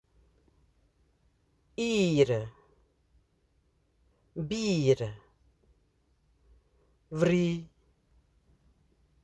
Accueil > Prononciation > ii > ii